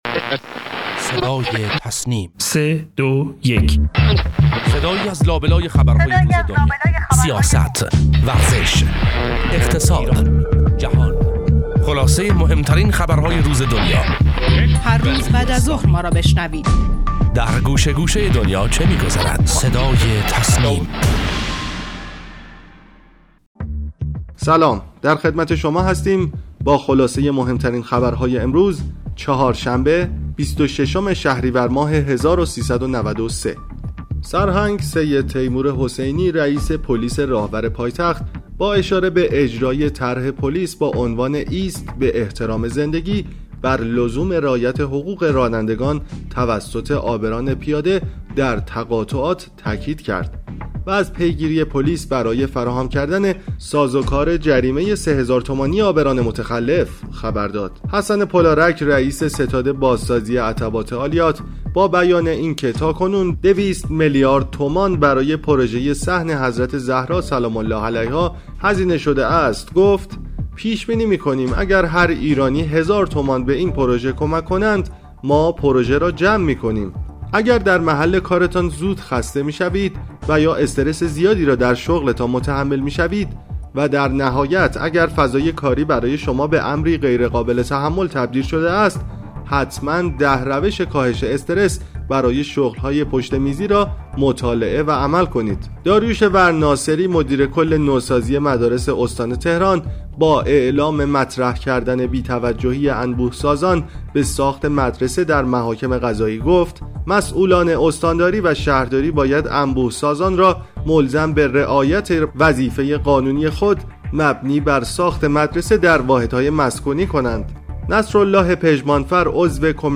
خبرگزاری تسنیم: مهمترین اخبار و گزارشات درباره موضوعات داخلی و خارجی امروز را از «صدای تسنیم» بشنوید.